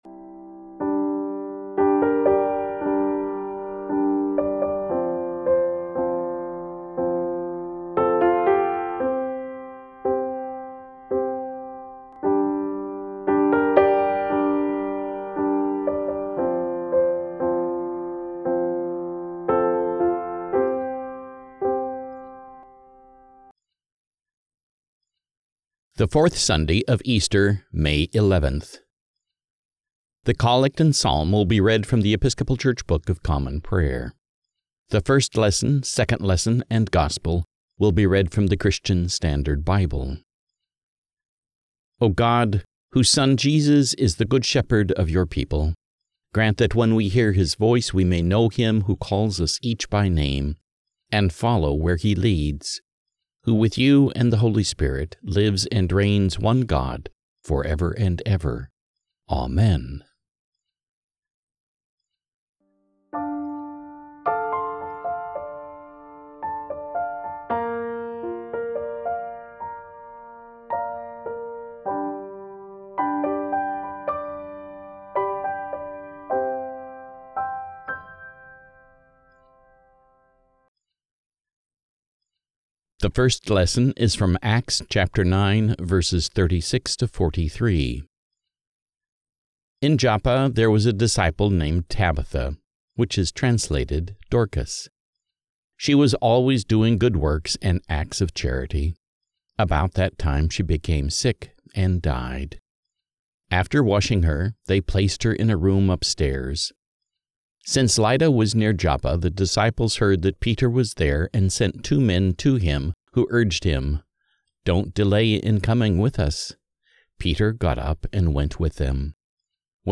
The Collect and Psalm will be read from The Episcopal Church Book of Common Prayer
The First Lesson, Second Lesson and Gospel will be read from the Christian Standard Bible